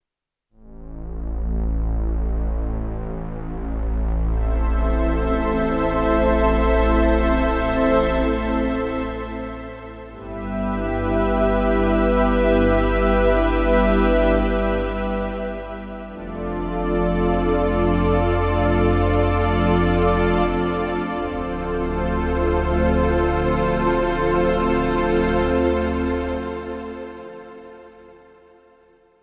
Arturia Polybrute - Morphing Analog Polysynth
Еще два таких "оркестрово-киношных" примерчика оставлю... Внутренние эффекты и мои кривые руки ) Вложения poly_strings.mp3 poly_strings.mp3 526,5 KB · Просмотры: 1.215 poly_runner.mp3 poly_runner.mp3 1.009,6 KB · Просмотры: 1.224